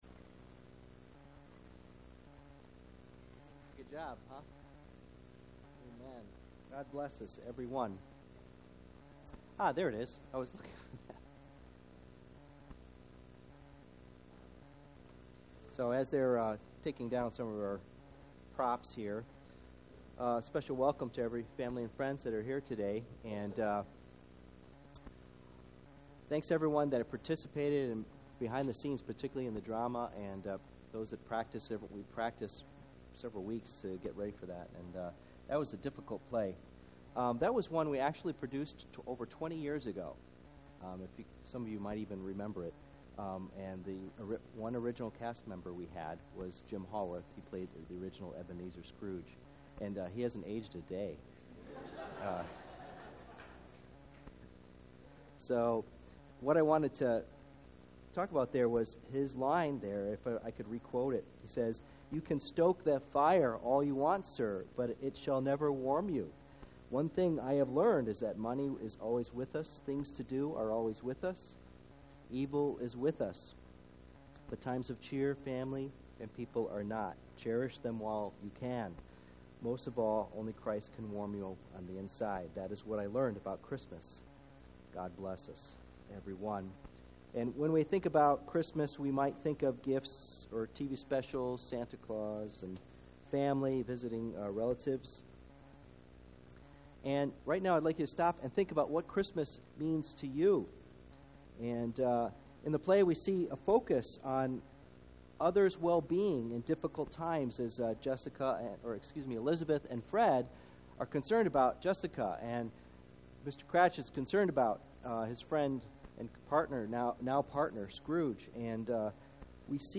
Christmas – Special Service